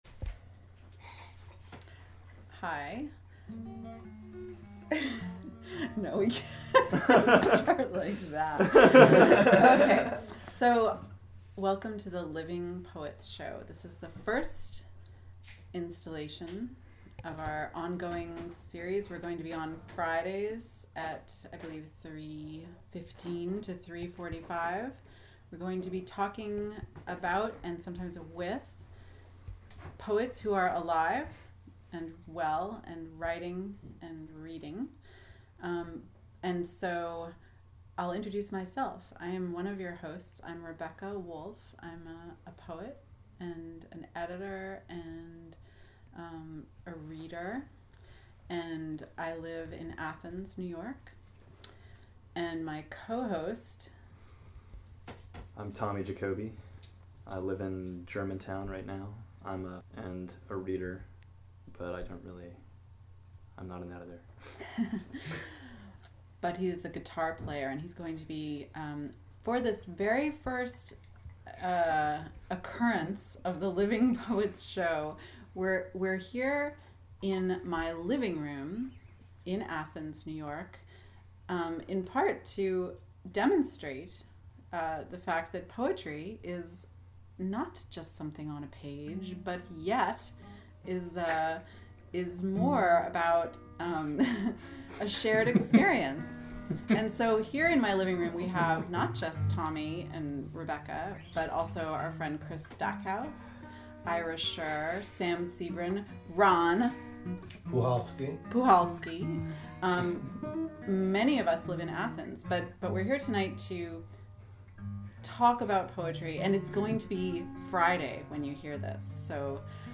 This is the inaugural Living Poets Show on WGXC, featuring conversation and readings on: dead poets.